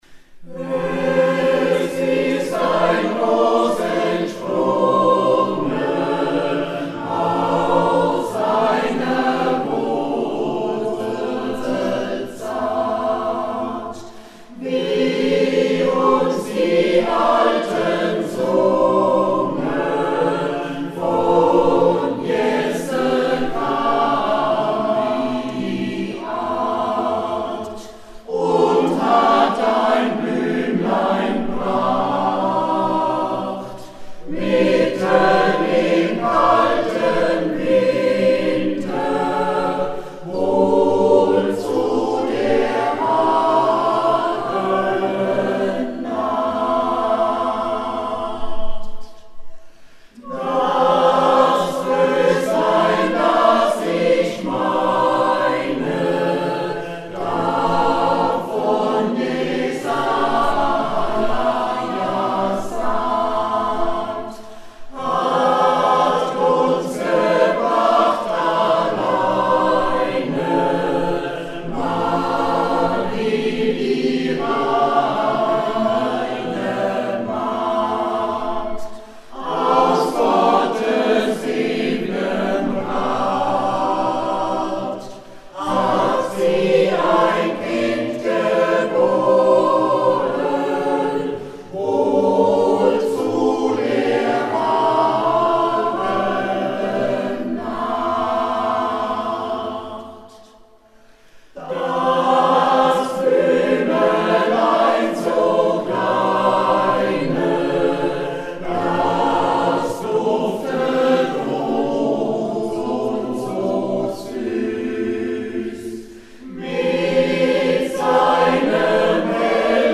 Zur Zeit besteht der Chor aus 42 Aktiven.
Liederaufnahmen des Kirchenchor: